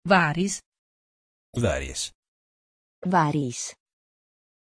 Pronunciation of Waris
Italian
pronunciation-waris-it.mp3